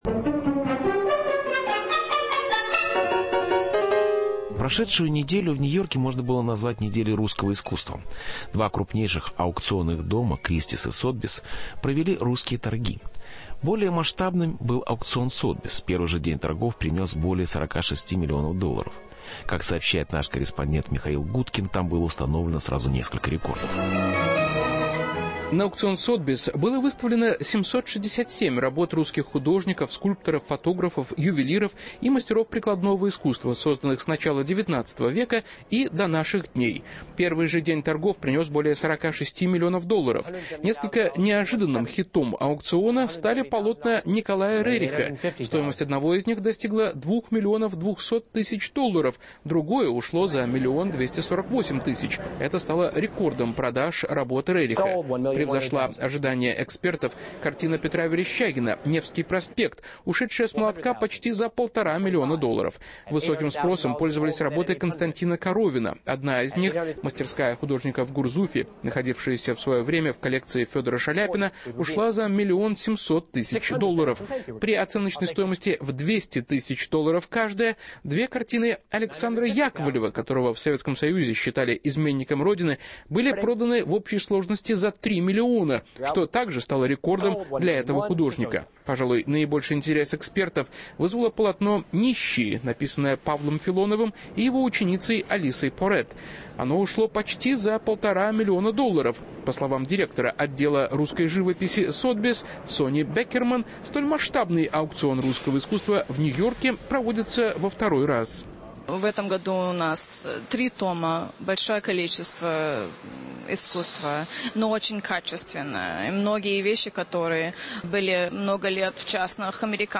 Интервью. Русское искусство на нью-йоркских аукционах